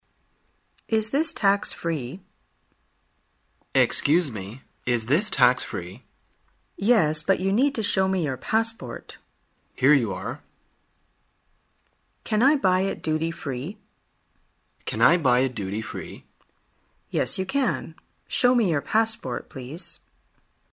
旅游口语情景对话 第275天:如何询问某物是否免税